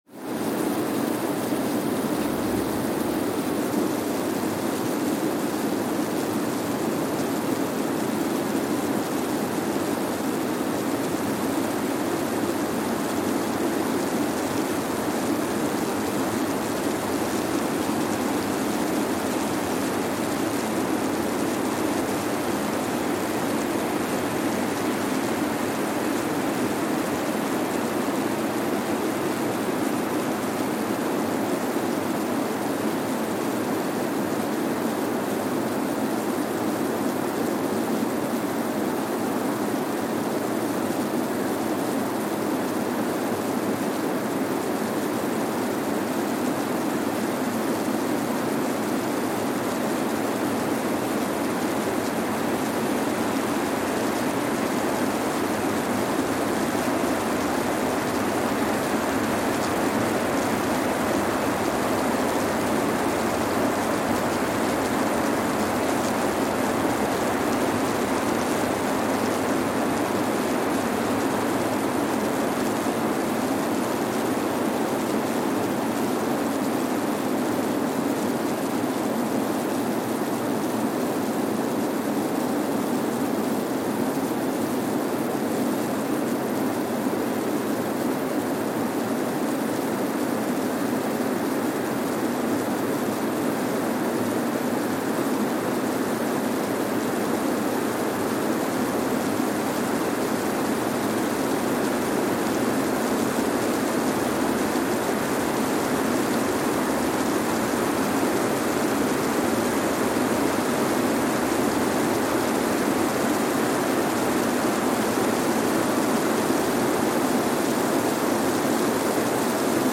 Kwajalein Atoll, Marshall Islands (seismic) archived on July 7, 2023
Station : KWJN (network: IRIS/IDA) at Kwajalein Atoll, Marshall Islands
Sensor : Streckeisen STS-5A Seismometer
Speedup : ×1,000 (transposed up about 10 octaves)
SoX post-processing : highpass -2 90 highpass -2 90